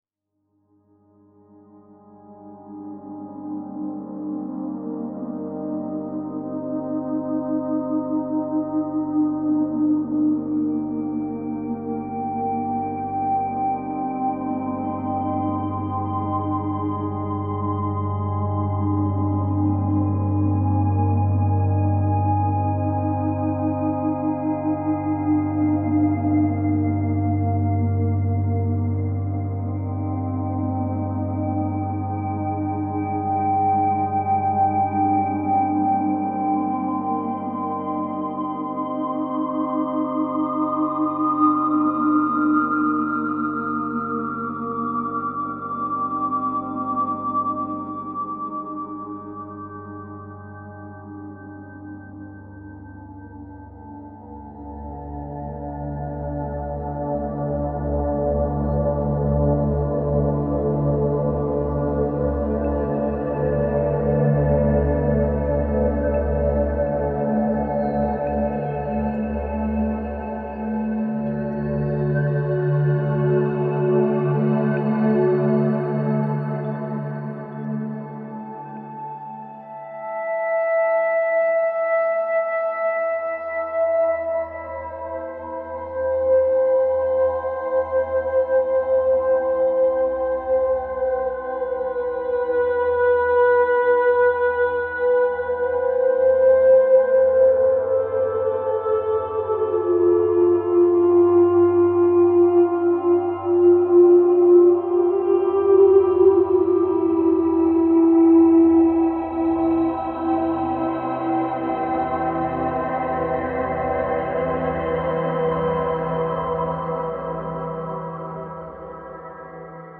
Die Ondes Martenot